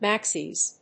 /ˈmæksiz(米国英語), ˈmæksi:z(英国英語)/